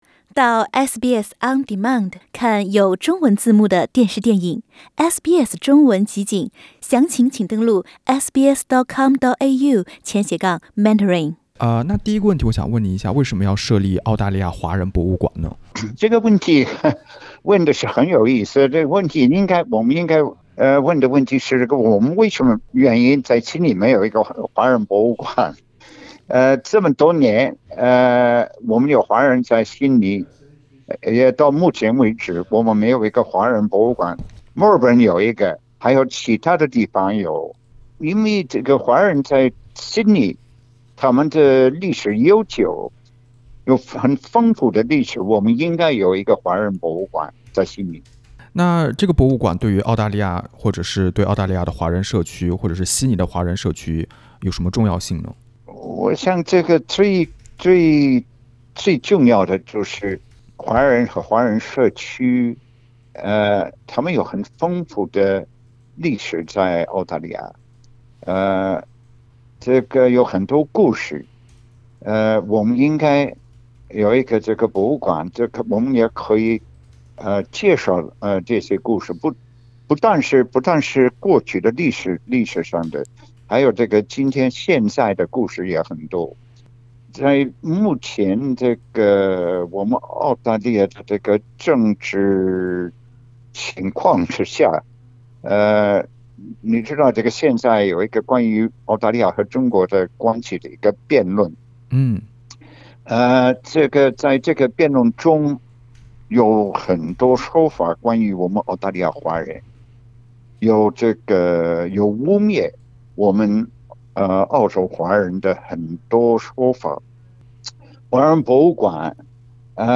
悉尼將設立首個澳洲華人博物館，博物館執行委員會成員，首席澳大利亞駐中華人民共和國大使費思博士（Dr Stephen FitzGerald AO）接受了本台採訪。